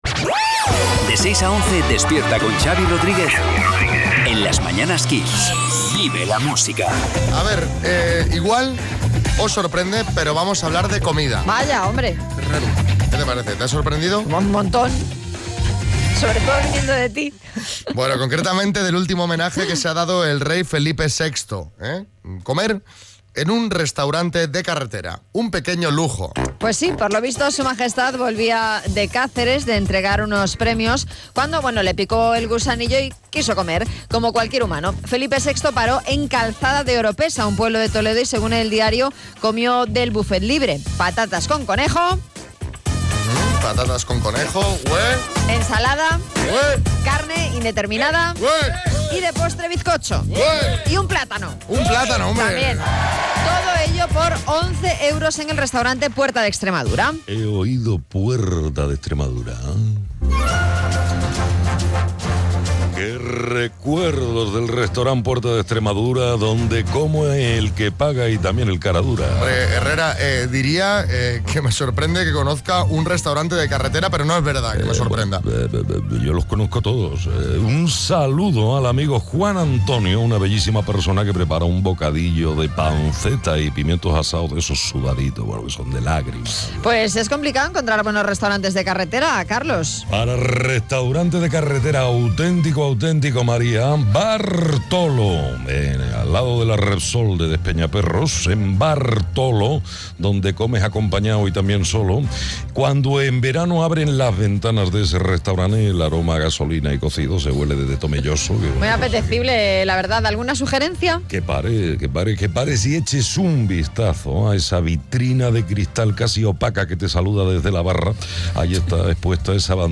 GAG-CARLOS-HERRERA-1205.mp3